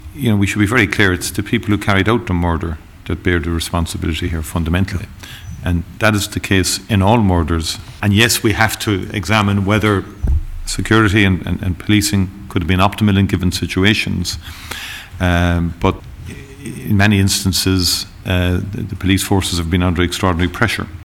Taoiseach Micheal Martin says the Donaldson family can refer their concerns to Fiosru, but he will also take the time to meet them……………..